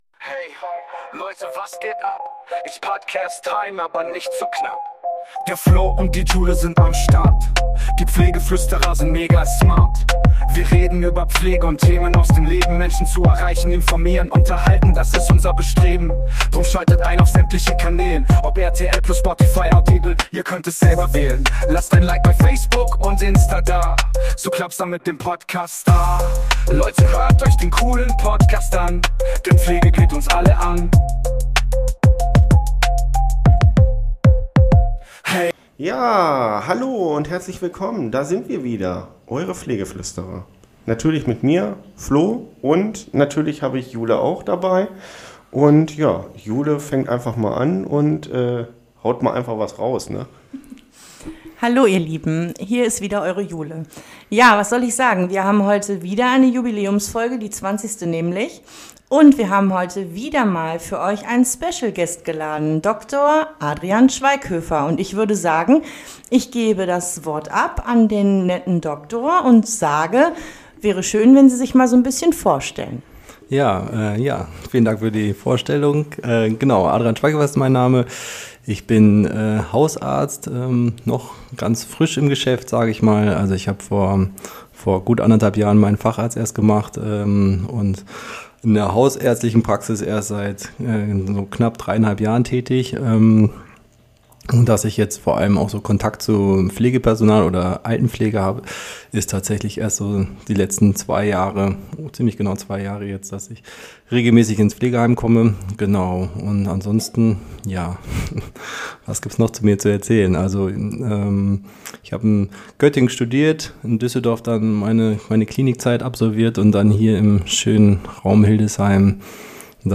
Heute zur 20zigsten Folge haben wir einen Hausarzt zu Gast. Der uns ein paar Fragen beantwortet, wie wichtig die Zusammenarbeit ist zwischen Pflegkraft und Arzt. Aber auch wie die Politik die Zusammenarbeit uns einfach erschwert.